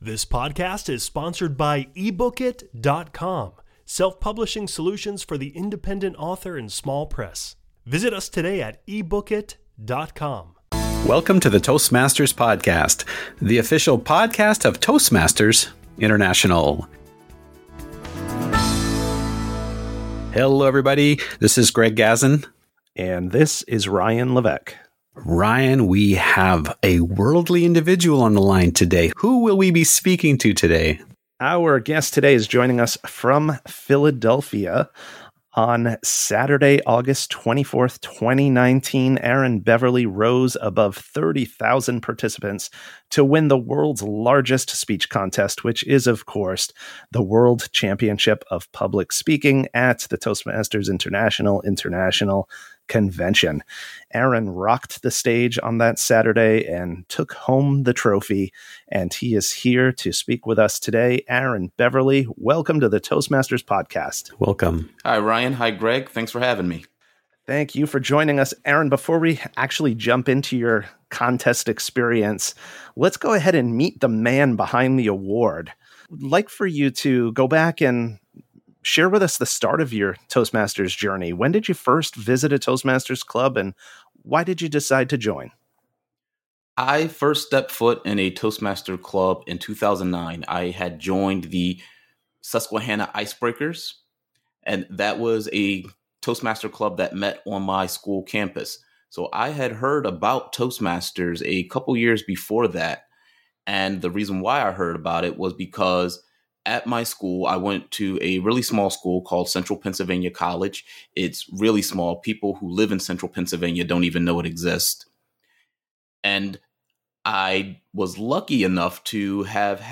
An inside look from a World Champ at what constitutes a winning speech. Plus, a bonus lightning round with questions from listeners!